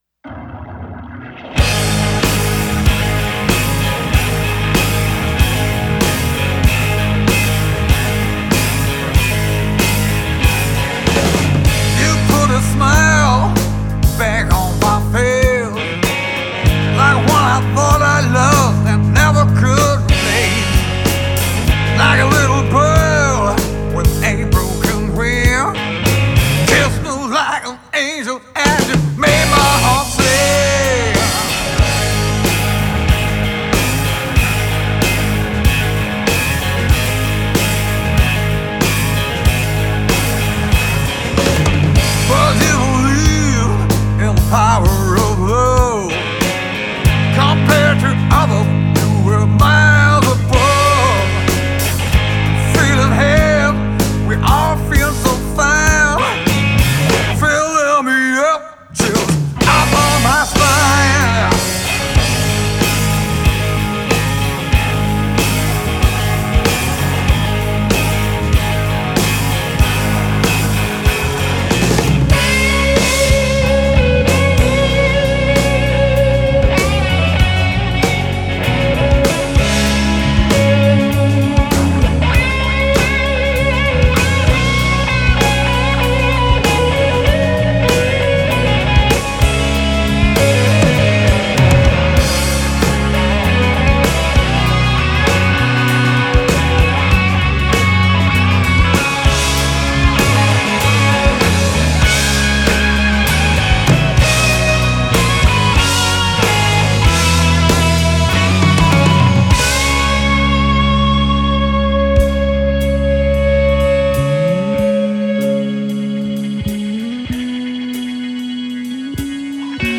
Rock&Blues